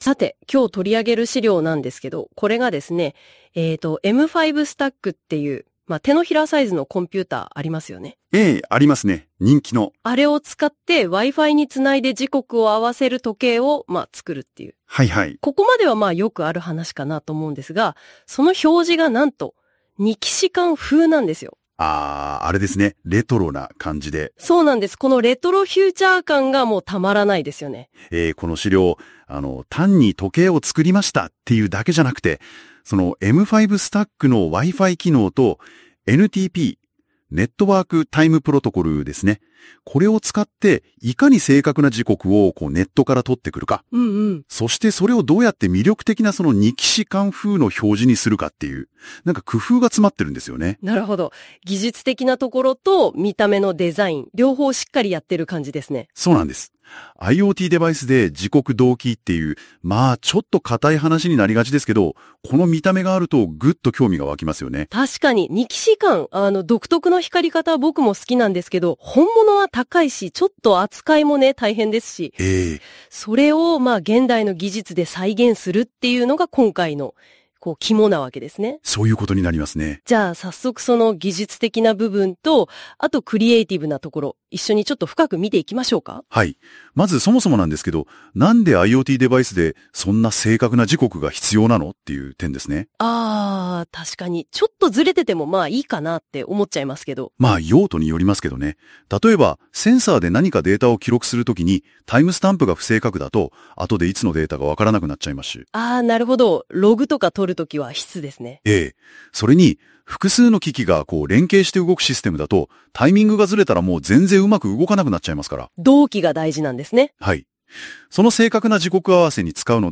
NotebookLM(ポッドキャスト)